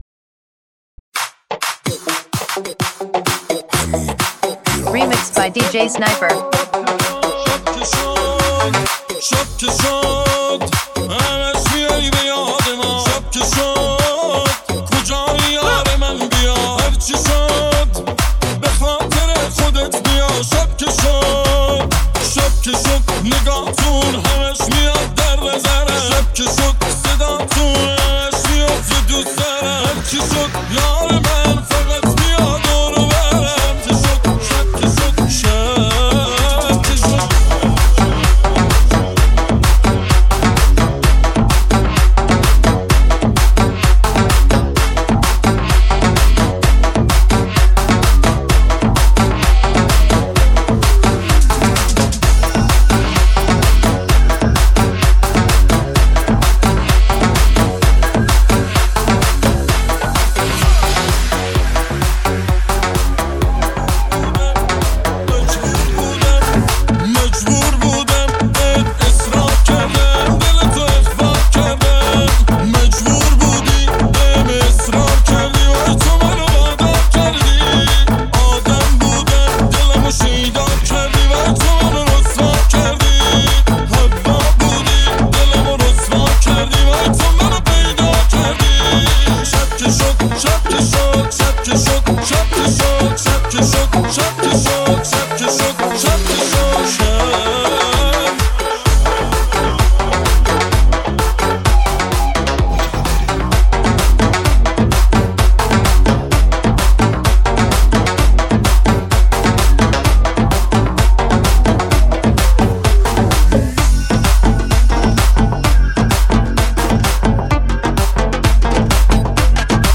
دانلود ریمیکس آهنگ شاد و جدیدریمیکسموزیک - عمومی